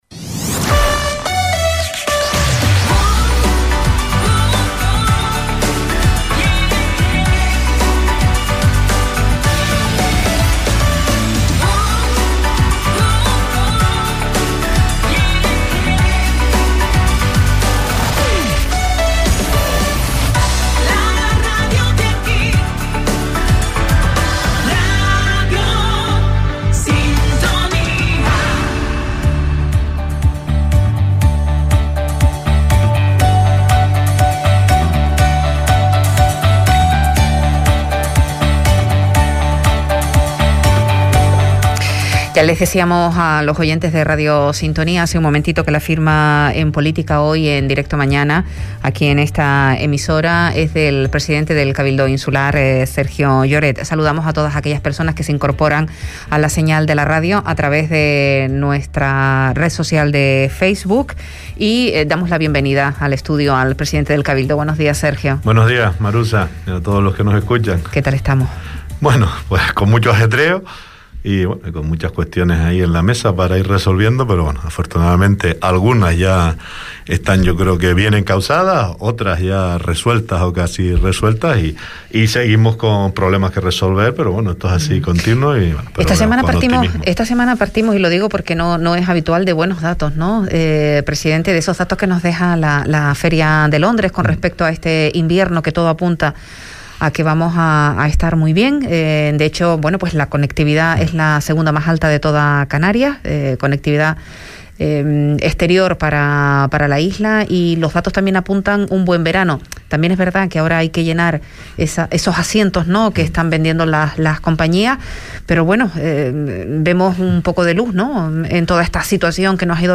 Entrevista a Sergio Lloret, presidente del Cabildo de Fuerteventura - 04.11.21 - Radio Sintonía
Entrevistas